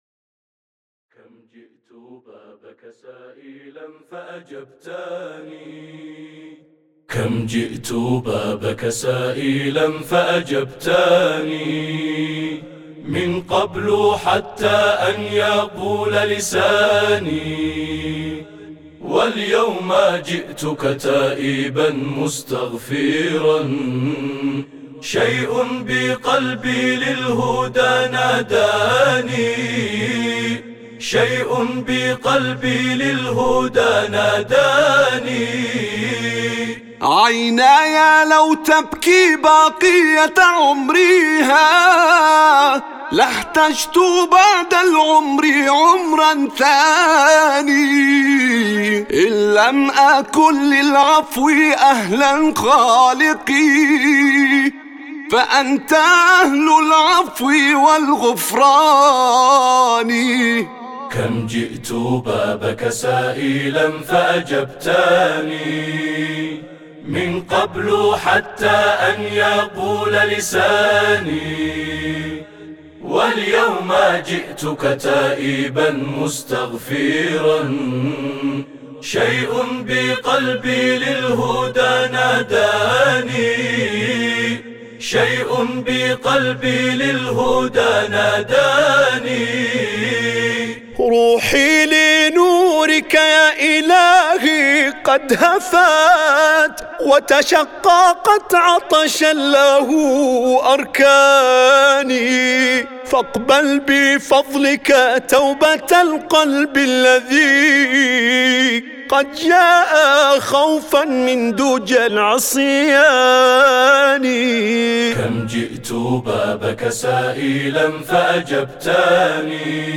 mp3 بدون موسيقى